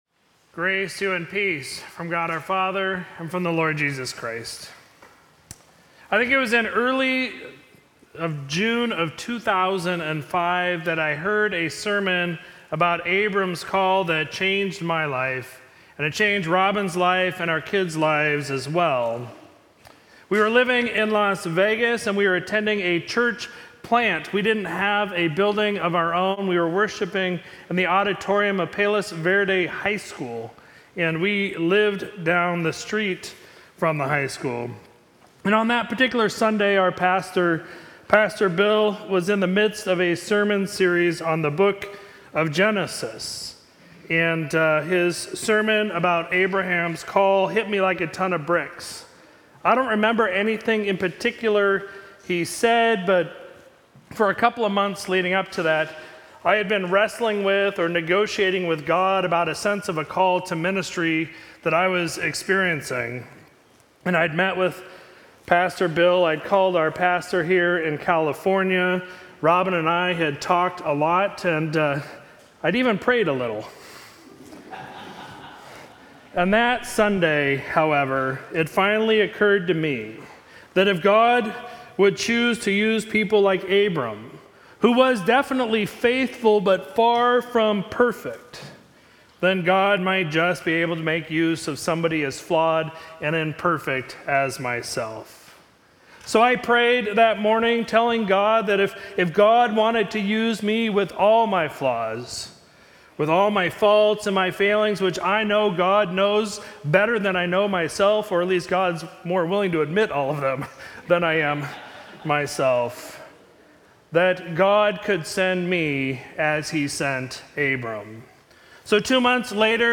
Sermon for Sunday, September 18, 2022